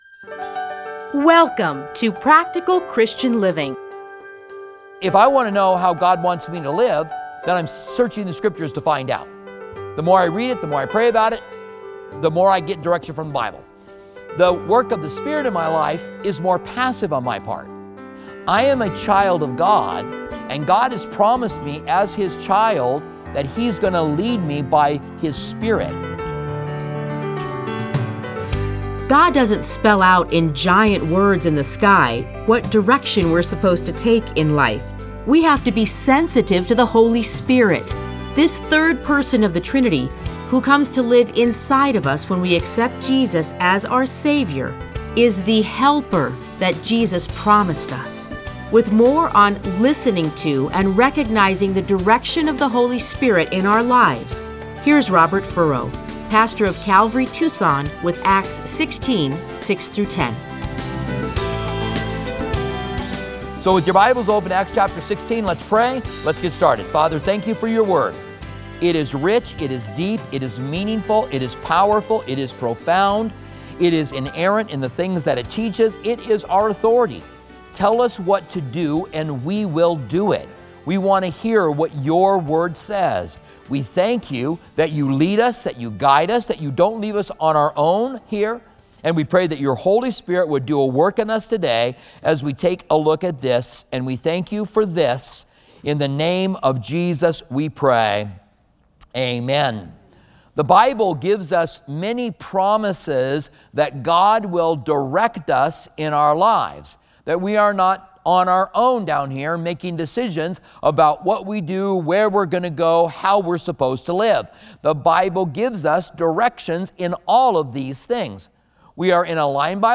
Listen to a teaching from Acts 16:6-10.